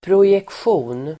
Uttal: [projeksj'o:n]